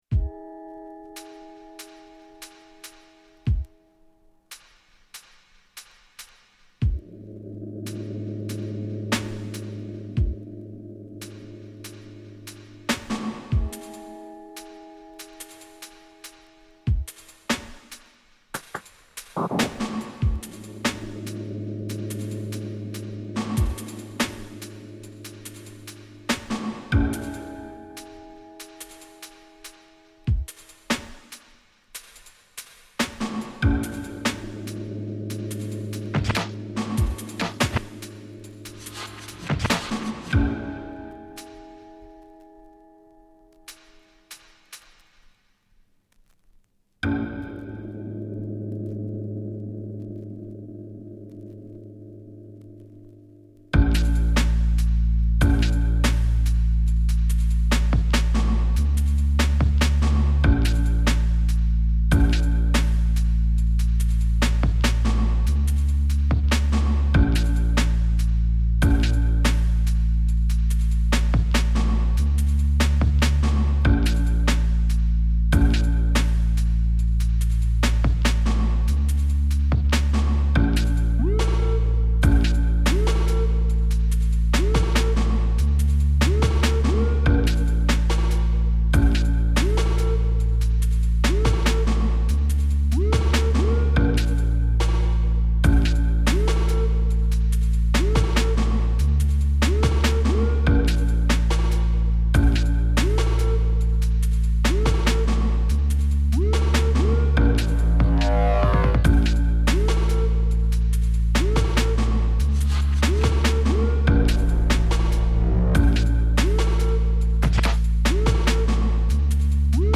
recorded this one last night.